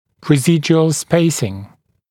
[rɪ’zɪdjuəl ‘speɪsɪŋ][ри’зидйуэл ‘спэйсин]оставшийся промежуток (промежутки, тремы) (например, после удаления зуба)